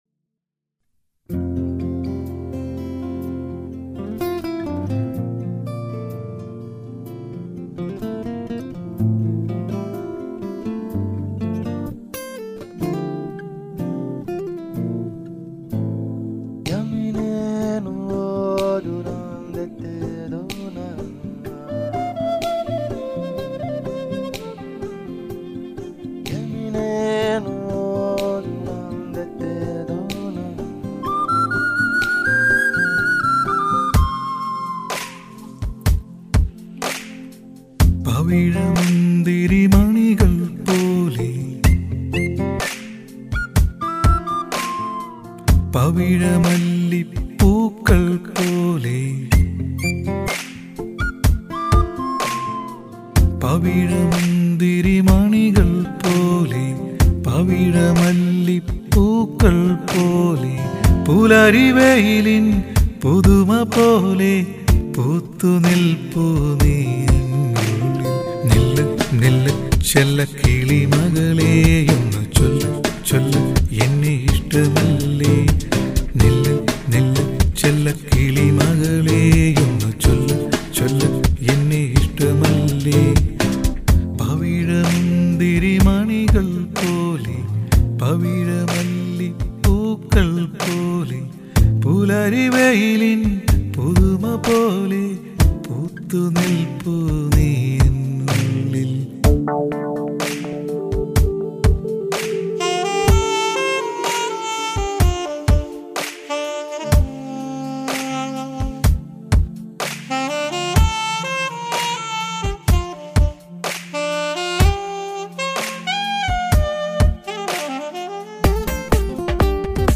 സ്റ്റുഡിയോ: രവീസ് സ്റ്റുഡിയോ, തൃശ്ശൂർ